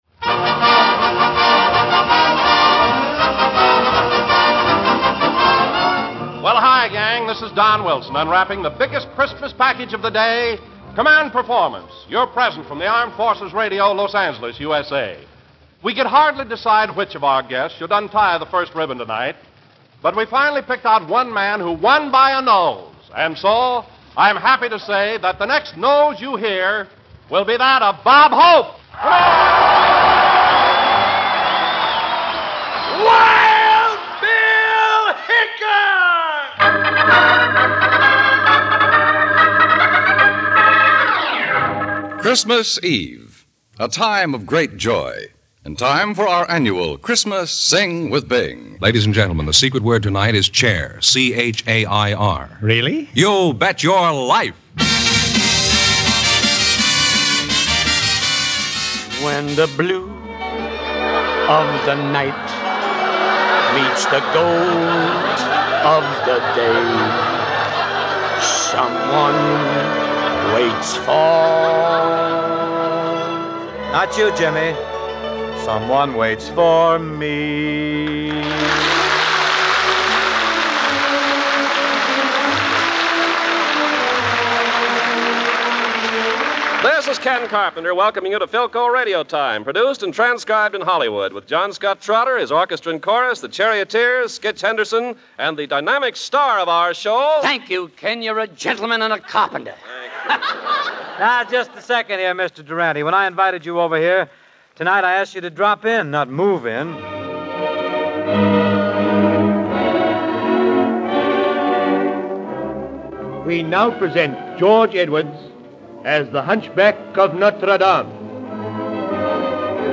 You'll find rare and obscure as well as mainstream radio shows from the 1930s, 1940s, and 1950s in the Radio Archives Treasures sets. These shows have all been restored with state-of-the-art CEDAR technology - the audio processing system used by major recording companies to restore older recordings. We expect the shows to be the best sounding copies available anywhere.